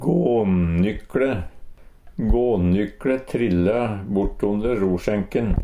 gånnykLe - Numedalsmål (en-US)
DIALEKTORD PÅ NORMERT NORSK gånnykLe garnnøste Eintal ubunde Eintal bunde Fleirtal ubunde Fleirtal bunde Eksempel på bruk GånnykLe trilla bortonder rosjenken.